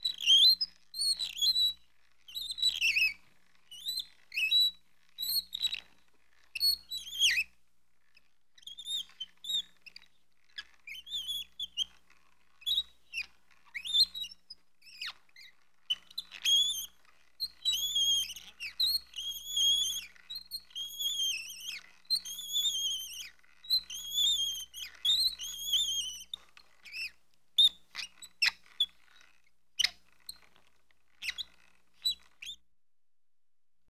У телеги скрипит колесо звук